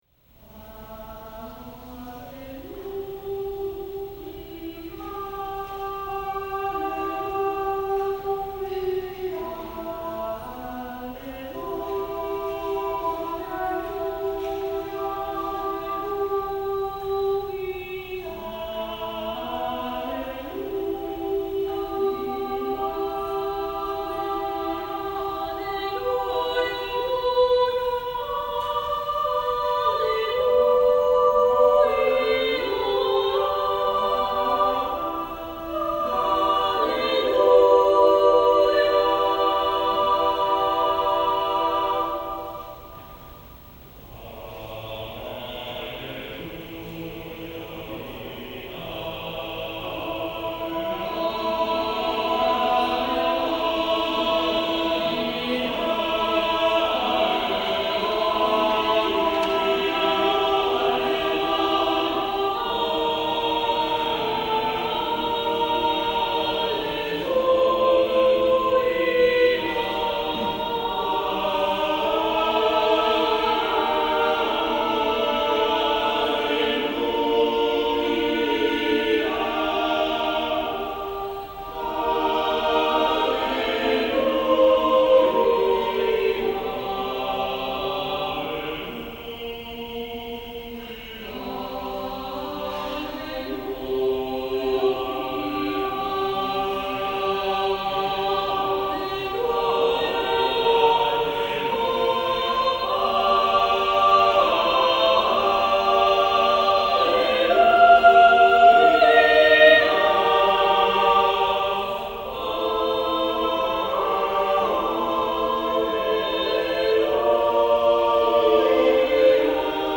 SATB Choir
Revised, with men's voices added